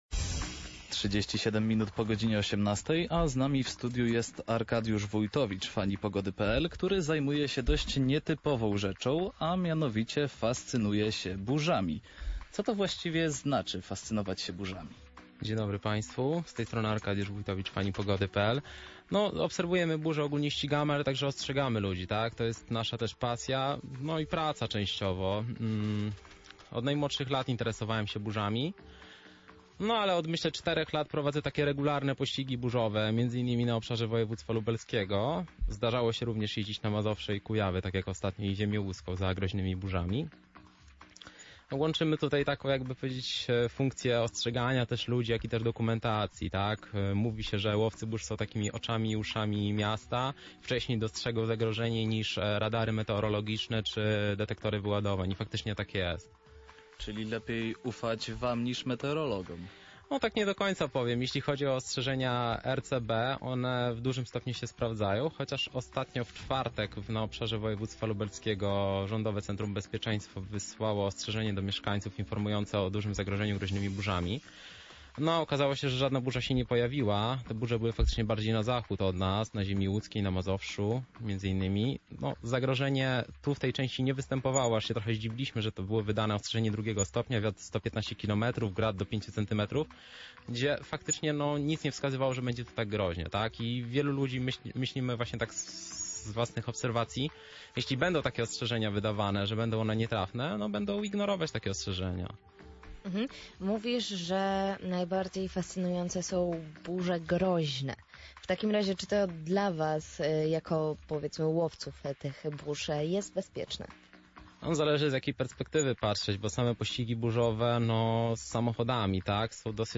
Rozmowa z gościem